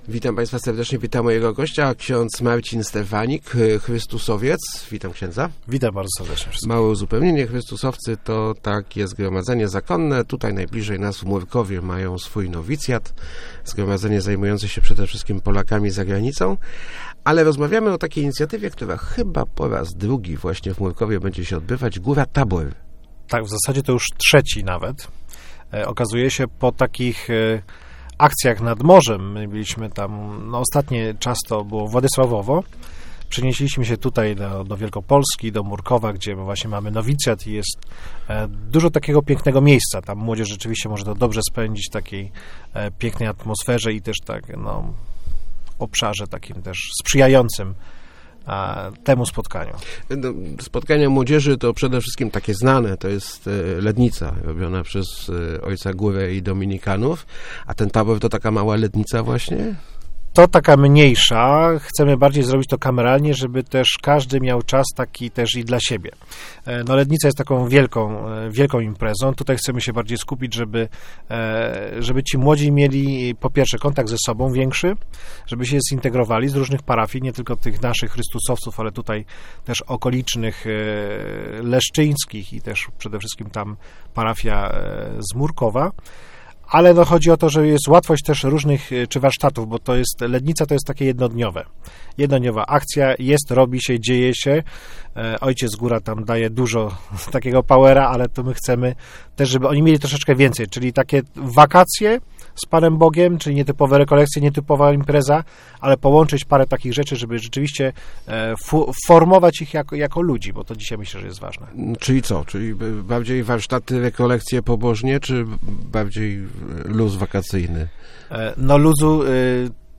Start arrow Rozmowy Elki arrow Góra Tabor w Mórkowie